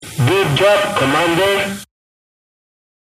good_job_commander.wav